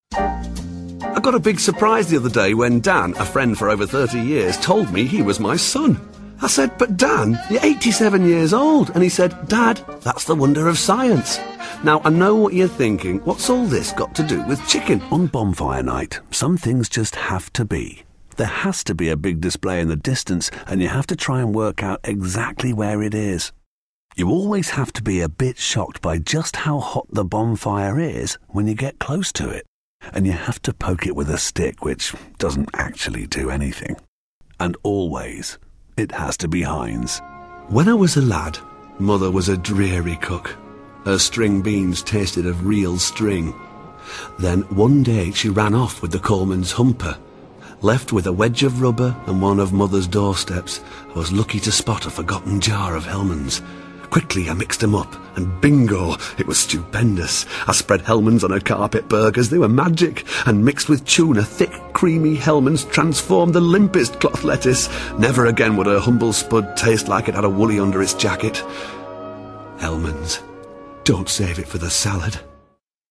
Commercial Showreel
Northern, Straight
Showreel, Commercials